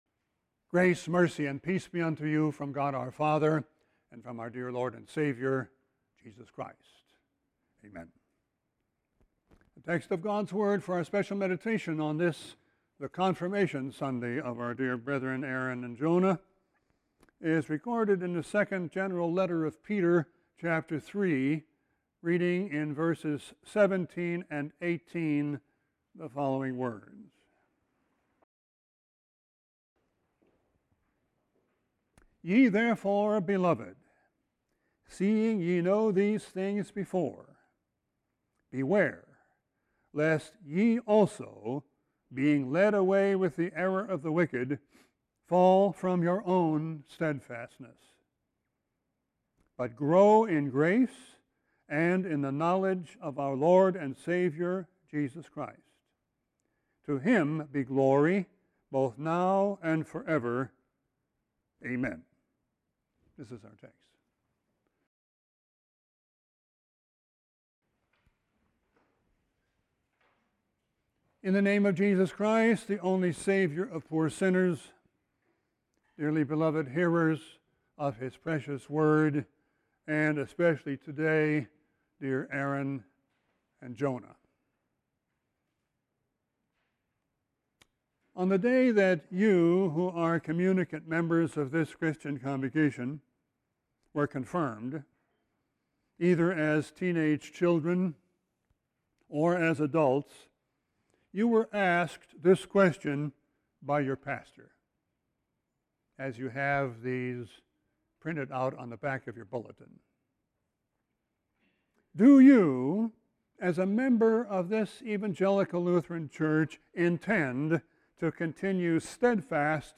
Sermon 6-28-20.mp3